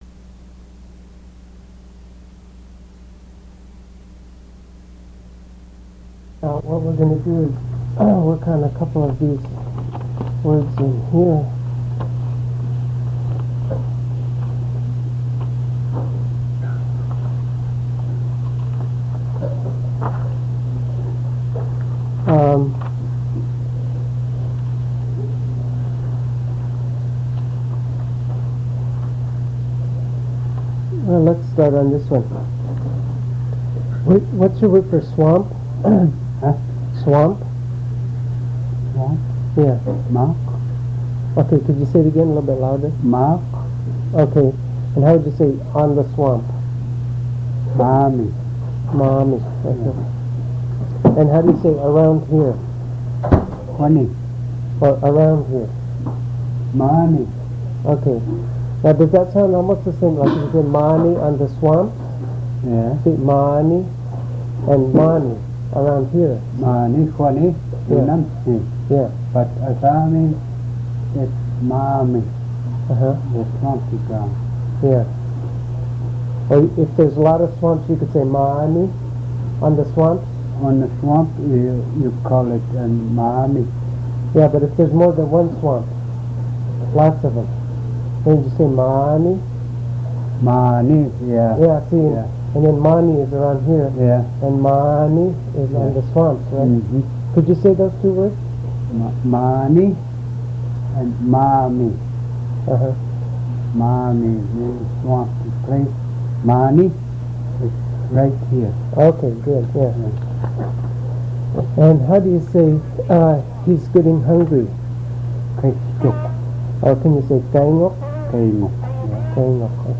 poor fairly quiet
prosody examples
Kodiak, Alaska